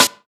• Airy Steel Snare Drum Sound B Key 123.wav
Royality free acoustic snare tuned to the B note. Loudest frequency: 4391Hz
airy-steel-snare-drum-sound-b-key-123-ZV3.wav